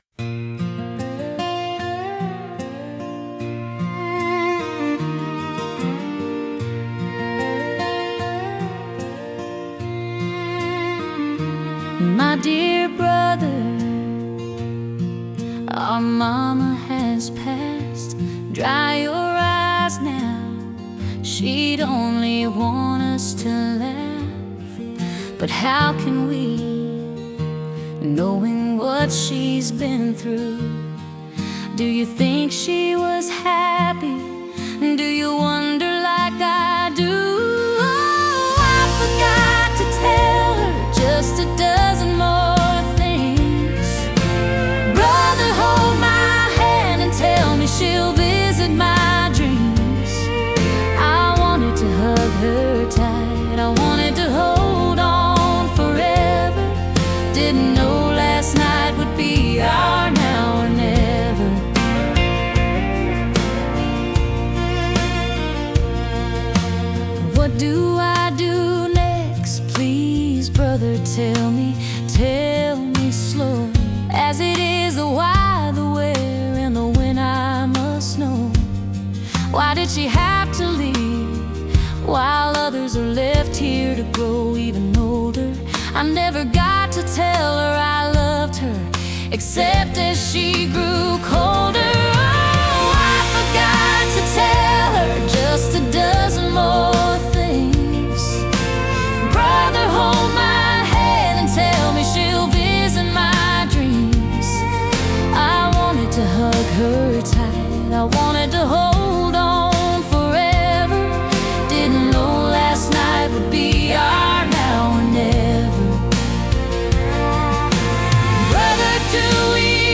Category: Country